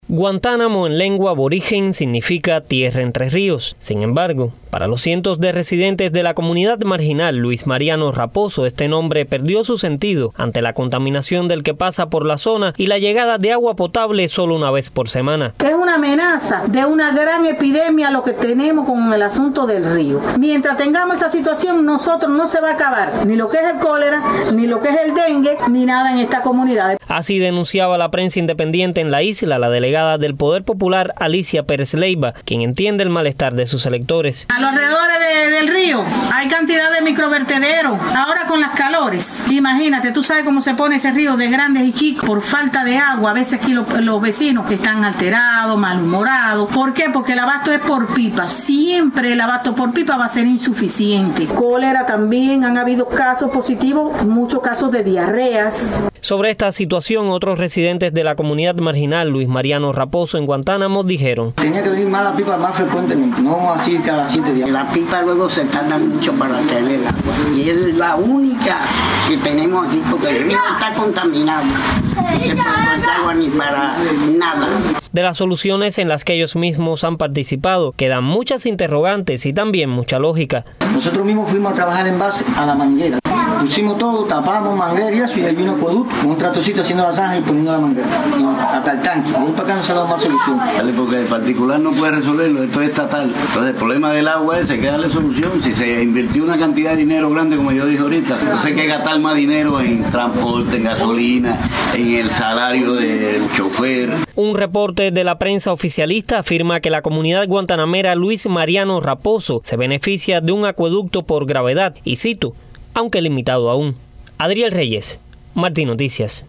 La comunidad Luis Mariano Raposo, ubicada al oeste de la ciudad de Guantánamo, sufre la escasez de agua y la contaminación de su río. La delegada del Poder Popular habla de una amenaza de epidemia y sobre el cólera.